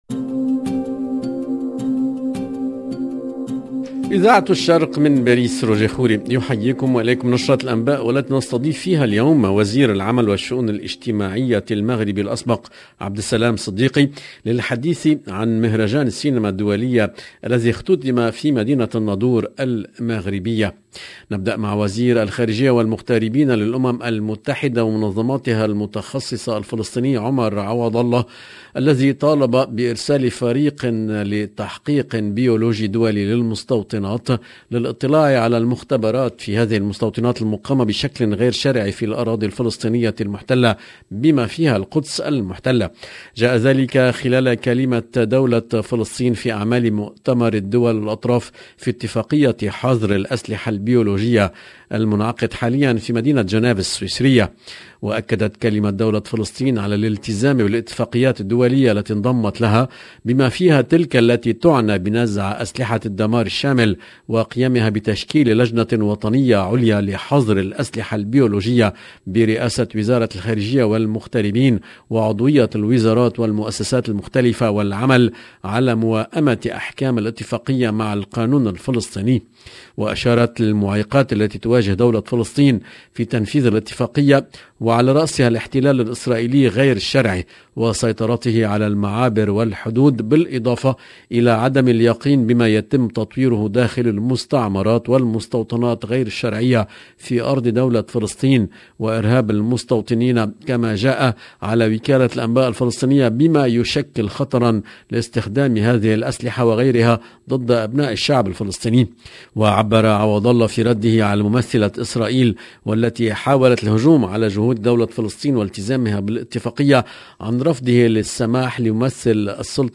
LE JOURNAL EN LANGUE ARABE DU SOIR DU 23/11/21